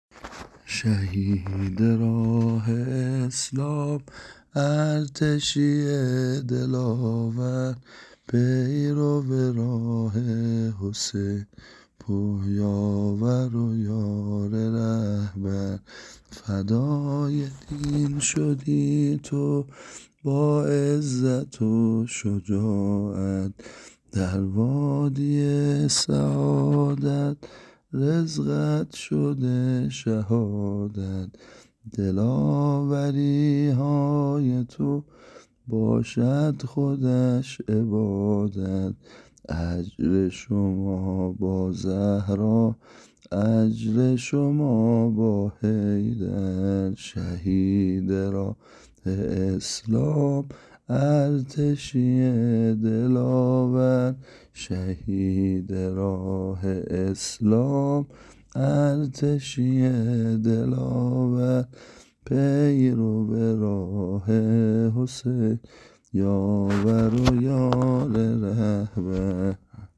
متن شعر نوحه برای شهدای عزیز ارتش جمهوری اسلامی ایران -(شهید راه اسلام، ارتشیِ دلاور)